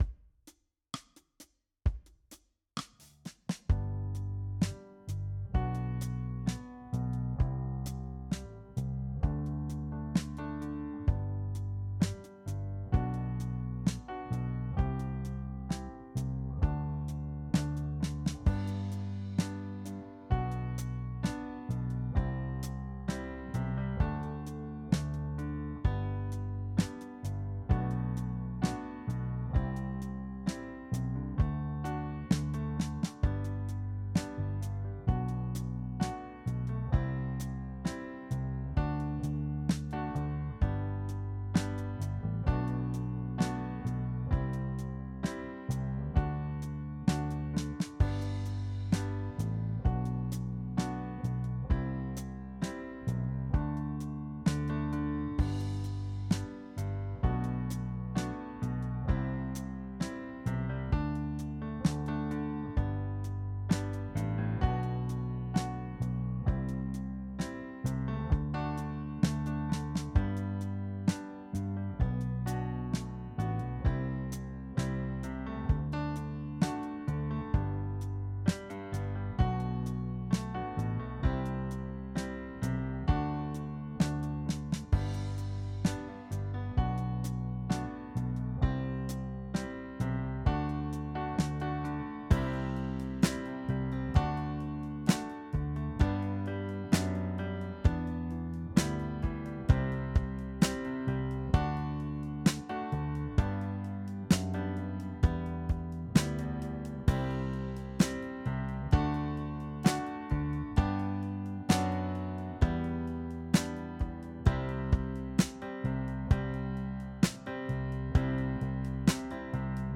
Jam Track
Jam track